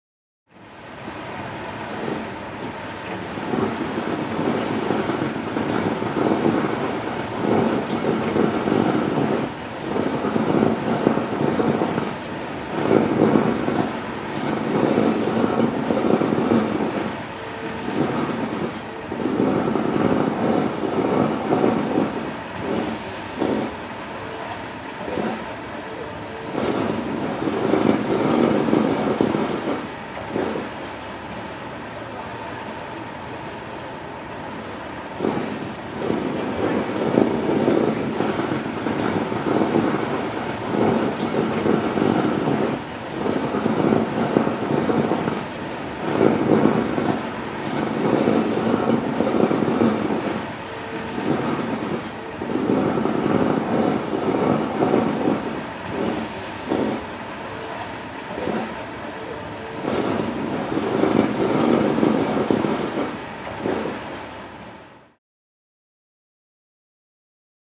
Звук корабля и парохода в доках